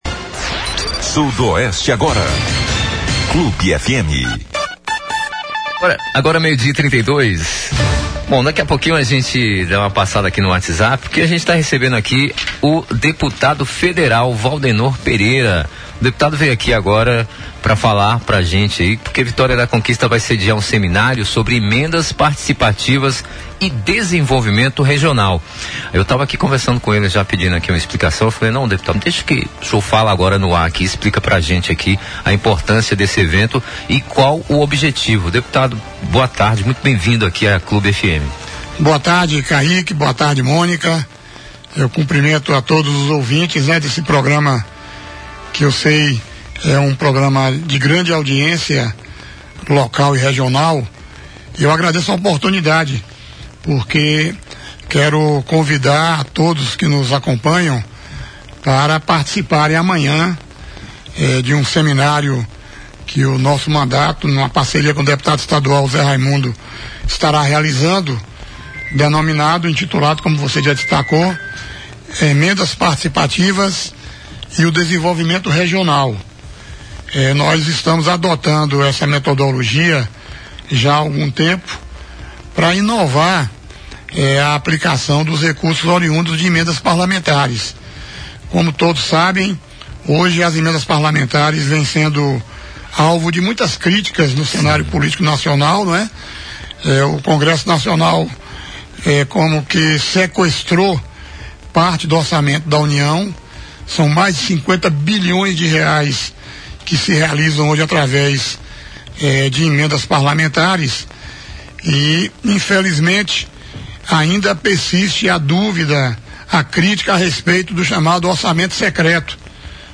Entrevista ao Vivo | Waldenor detalha Emendas Participativas e critica o Governo Municipal de Vitória da Conquista | BLOG DO ANDERSON
Após um longo hiato, o deputado federal Waldenor Alves Pereira Filho (PT-BA) retornou ao estúdios d Rádio Clube de Conquista nesta sexta-feira (20).